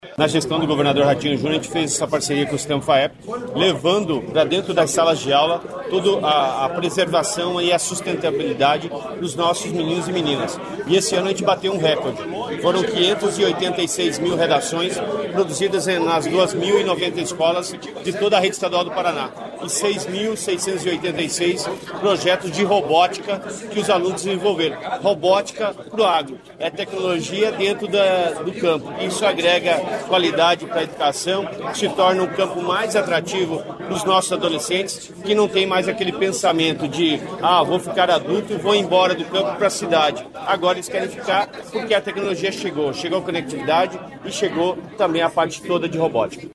Sonora do secretário Estadual da Educação, Roni Miranda, sobre a premiação do Concurso Agrinho 2025